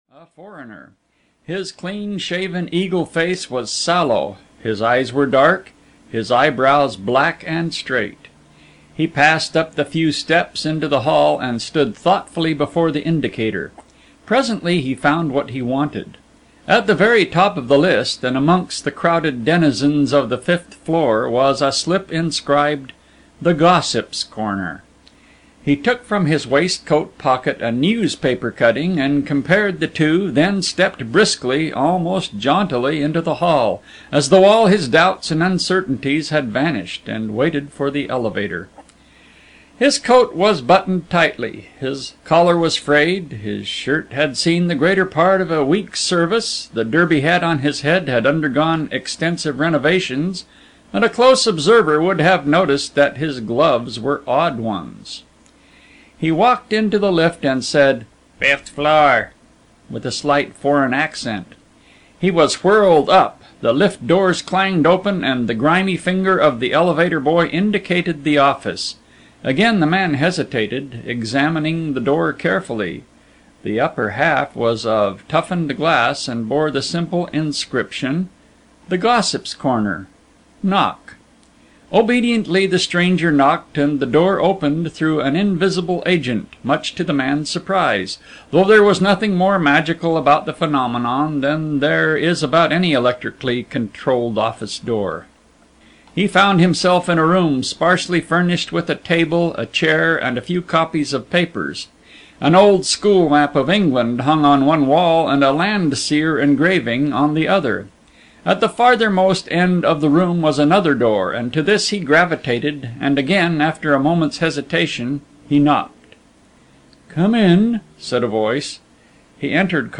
The Secret House (EN) audiokniha
Ukázka z knihy